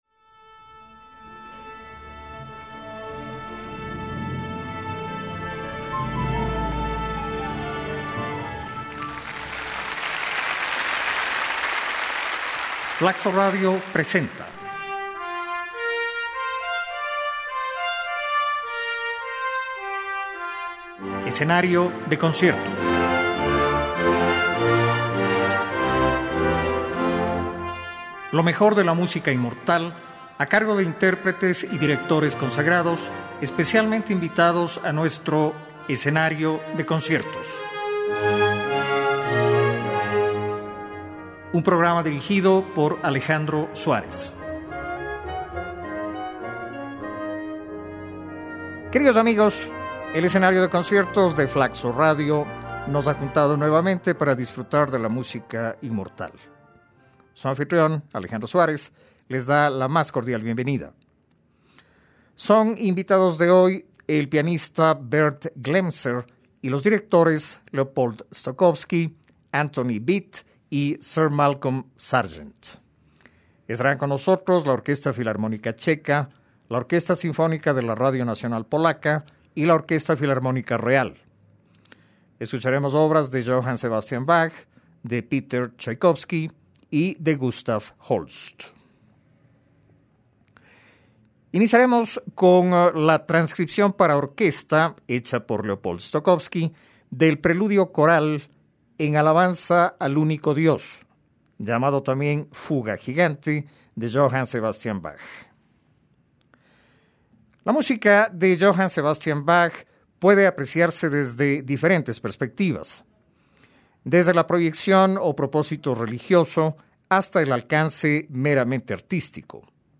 Carmina Burana, la composición más difundida de Carl Orff, ha sido arreglada de diversas maneras una de ellas para piano solo.
quinto concierto para piano
con el chileno Claudio Arrau como solista
Bach volverá al Escenario con uno de sus conciertos para violín, el BWV 1042, y con la versión orquestal hecha por Leopold Stokowsky de uno de sus corales para órgano, el denominado "Fuga Gigante". El piano será, ciertamente, el principal protagonista de los conciertos de esta semana.